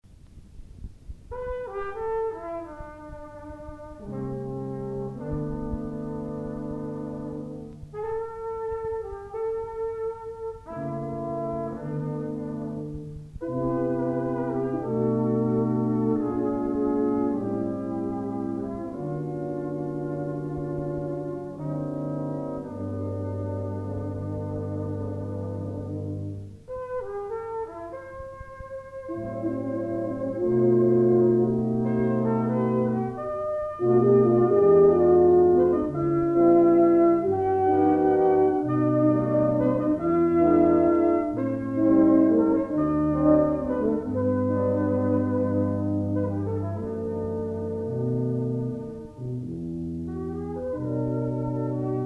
BRASS SEXTETS
2 Trumpets, Horn/Trombone, 2 Trombones, Tuba.
One slow and one fast movement in jazz feeling